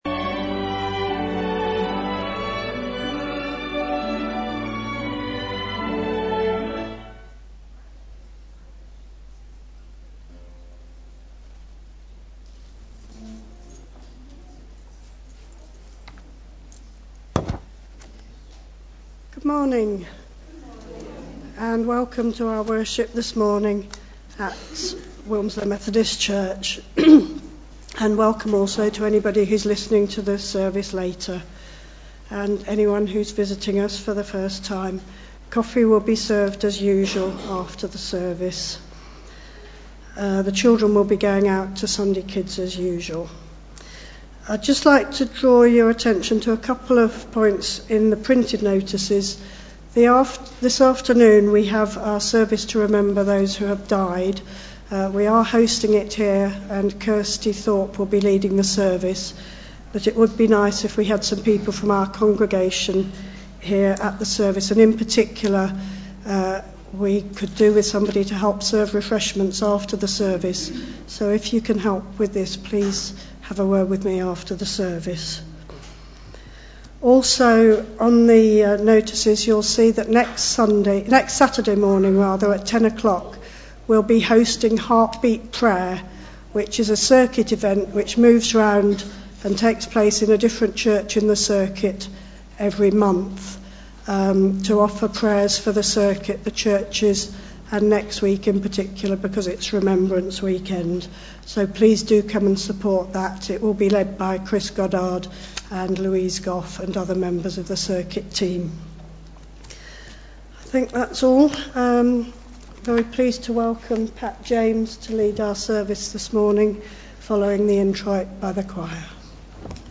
2019-11-03 Morning Worship
Genre: Speech.